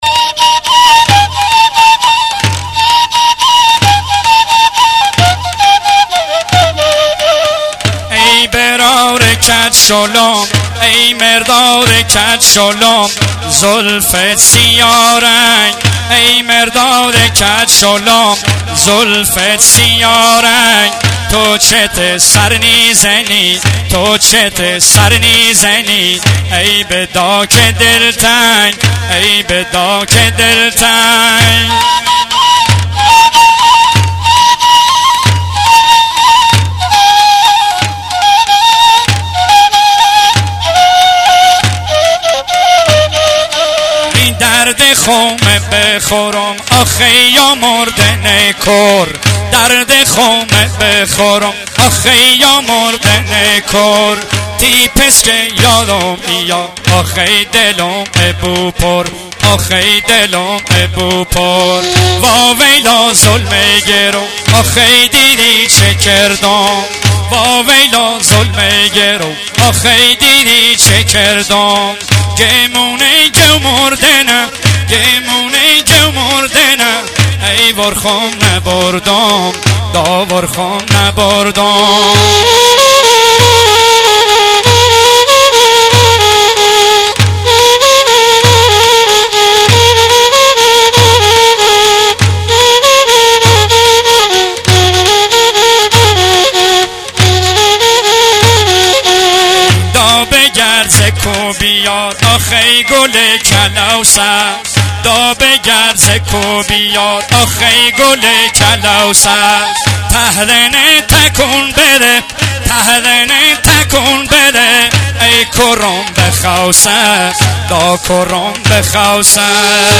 دندال غمگین لری بختیاری
دانلود عزاداری و سوگواری لری بختیاری
سبک ها: دندال (دوندال)، گاگریو – گویش: بختیاری